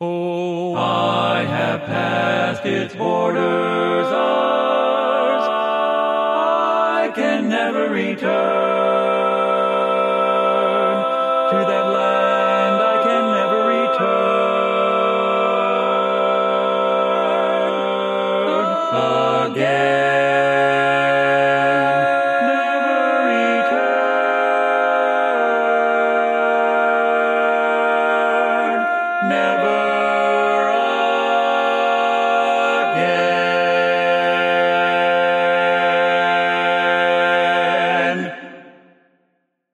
Key written in: F Major
How many parts: 4
Type: Barbershop
All Parts mix:
Learning tracks sung by